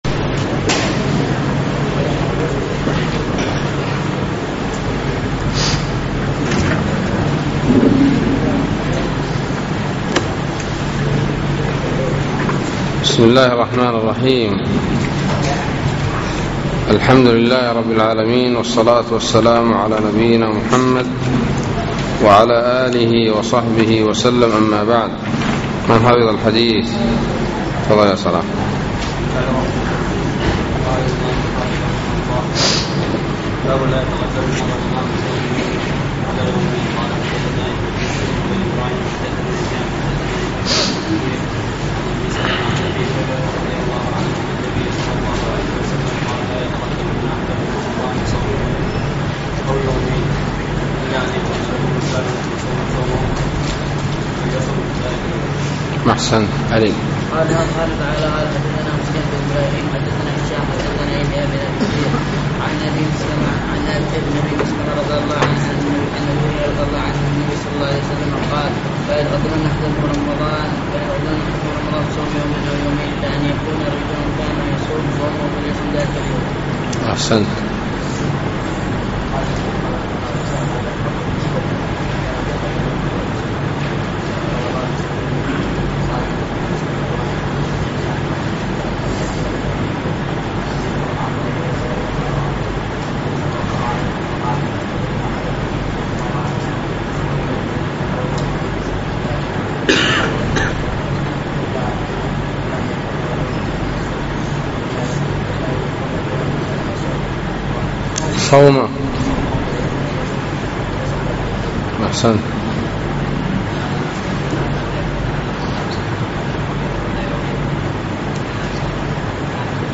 الدرس الثالث عشر